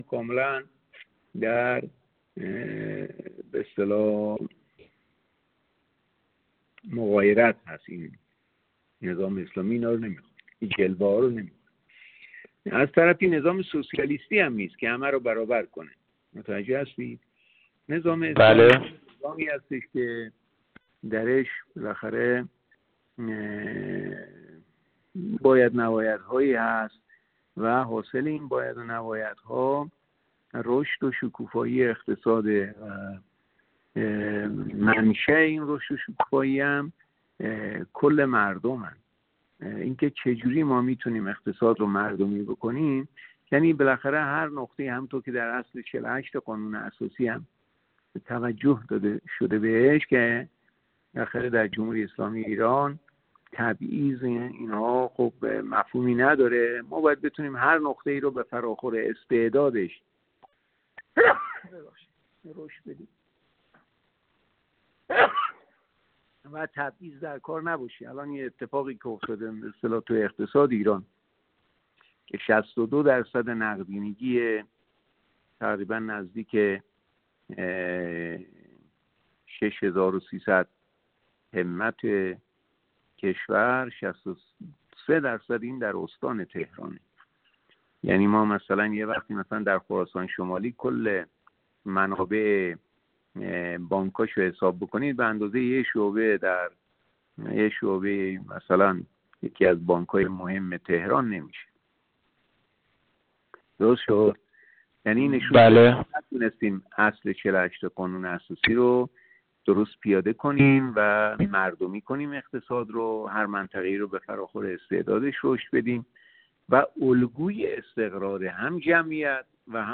هادی قوامی، معاون امور حقوقی و مجلس وزارت اقتصاد و امور دارایی در گفت‌وگو با ایکنا، با بیان اینکه نظام جمهوری اسلامی ایران نظام سرمایه‌داری نیست، اظهار کرد: در نظام سرمایه‌داری بنگاه‌هایی فعالیت دارند که فقط نفع شخصی را در نظر می‌گیرند و این بنگاه‌ها تأثیر بسیار زیادی بر سازوکارهای اقتصادی و حتی کلان‌تر از آن دارند اما چون نظام جمهوری اسلامی ایران مبتنی بر مردم و مشارکت‌های مردمی است، آرمان‌هایش به دور از نظام‌های سرمایه‌سالارانه است.